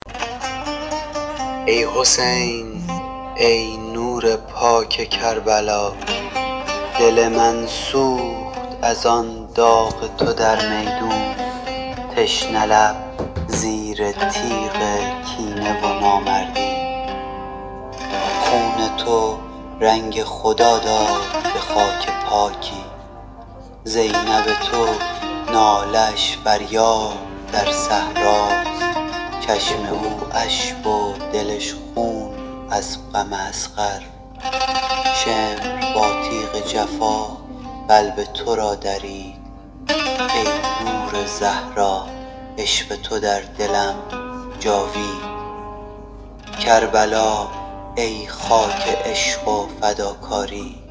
بریم دو نمونه مرثیه که تماما با هوش مصنوعی ساخته شده رو با هم گوش کنیم.